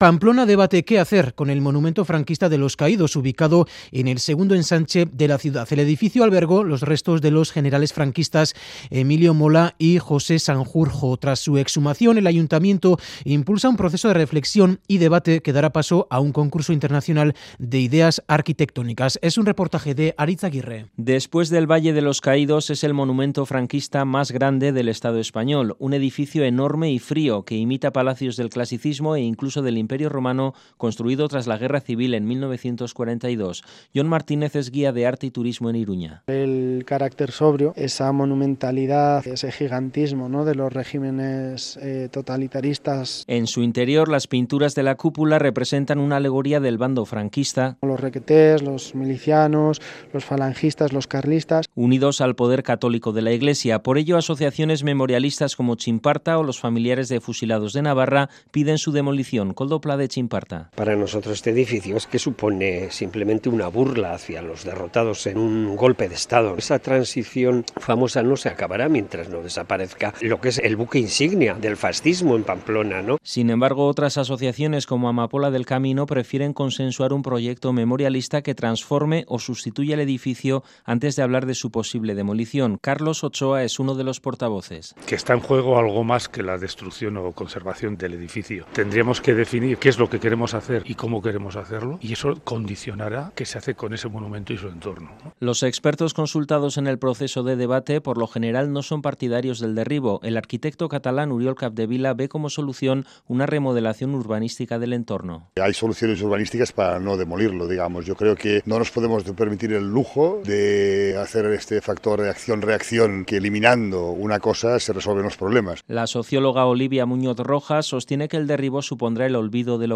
Radio Euskadi REPORTAJES ¿Qué hacemos con Los Caídos?